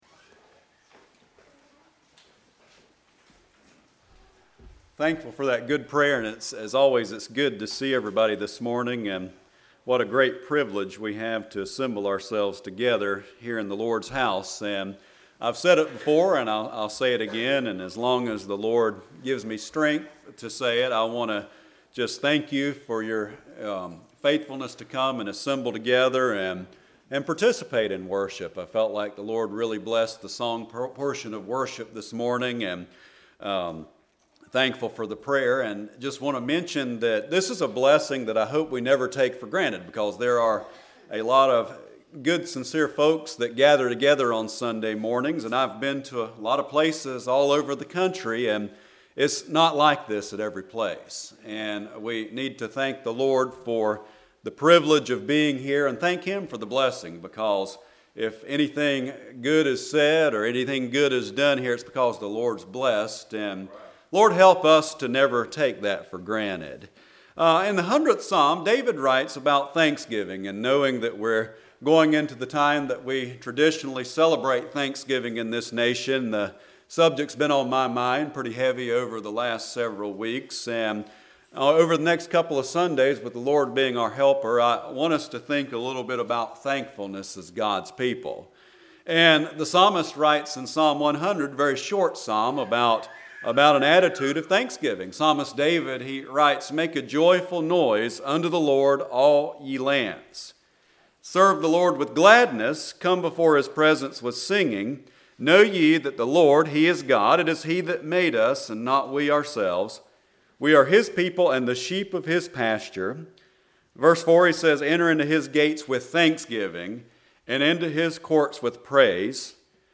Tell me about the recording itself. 11/17/19 Sunday Morning